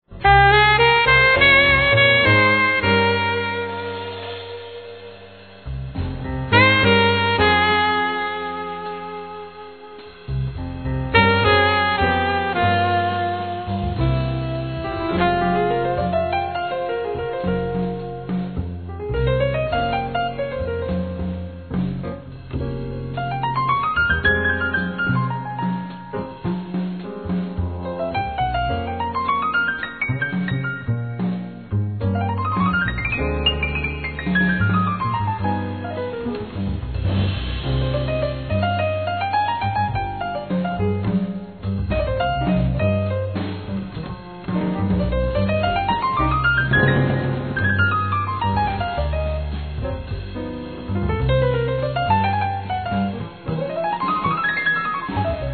Saxphone,Flute
Piano
Drums
Double bass